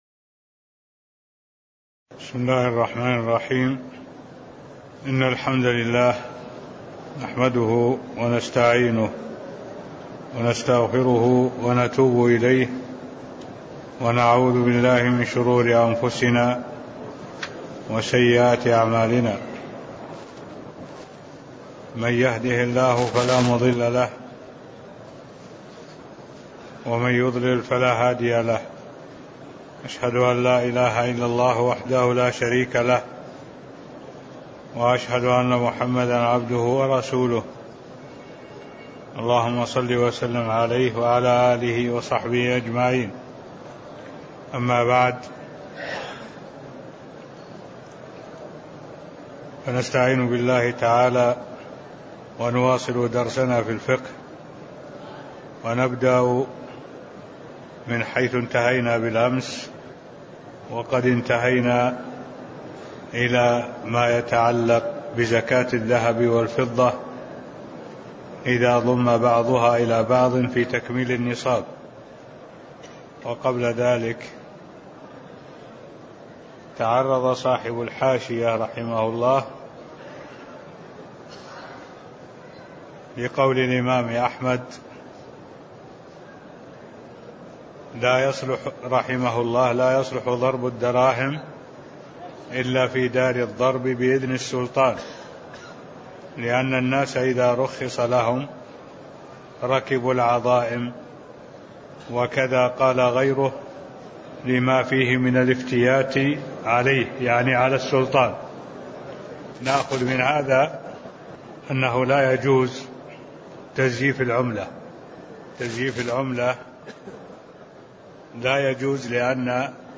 تاريخ النشر ٢٨ ربيع الثاني ١٤٢٩ هـ المكان: المسجد النبوي الشيخ: معالي الشيخ الدكتور صالح بن عبد الله العبود معالي الشيخ الدكتور صالح بن عبد الله العبود زكاة الذهب والفضة (006)